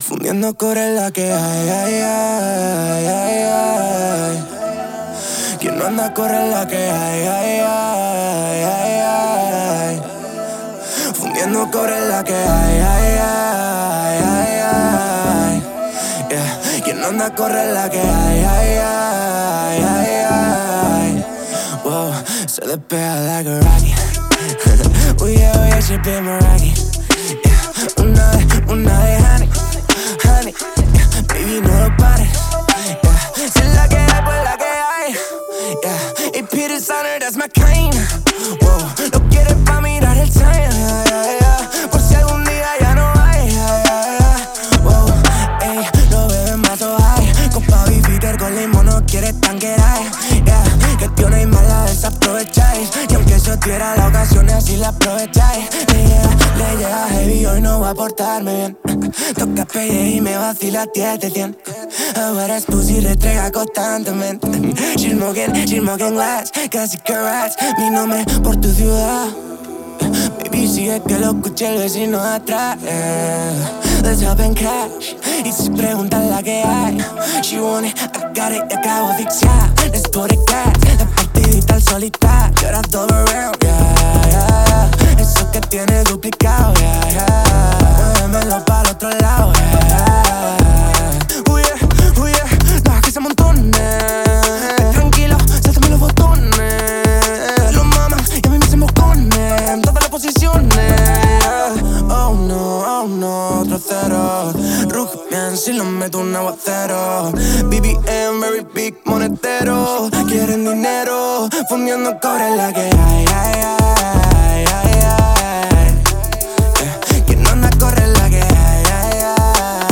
Drum & Bass, Glamorous, Energetic, Quirky, Restless, Sexy